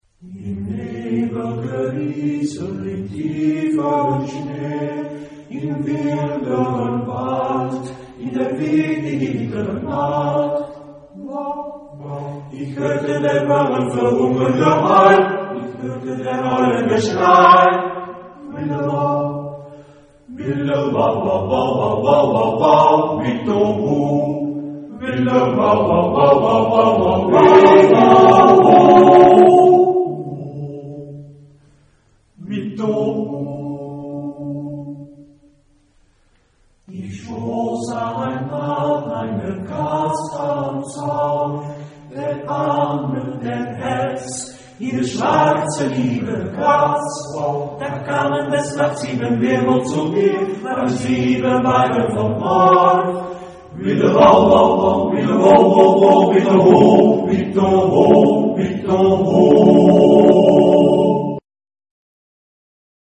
Genre-Style-Form: Romantic ; Secular ; Lied ; ballad
Type of Choir: TTBB  (4 men voices )
Tonality: G minor